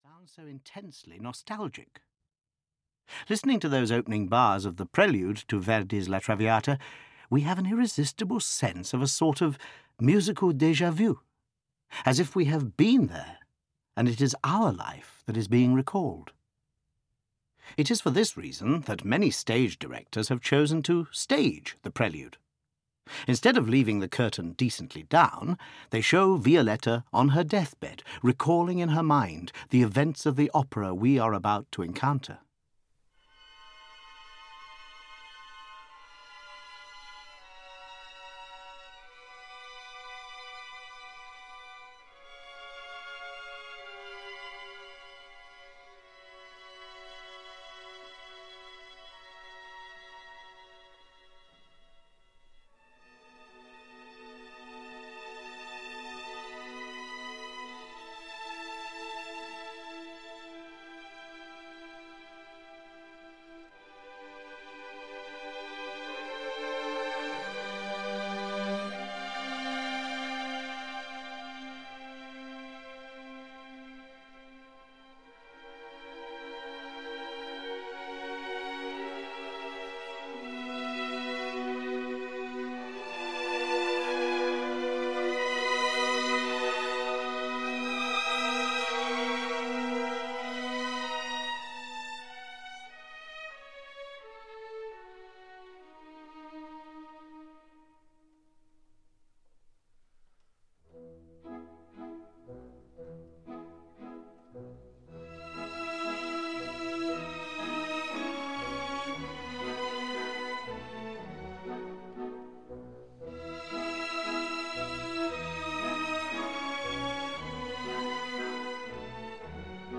Opera Explained – La Traviata (EN) audiokniha
Ukázka z knihy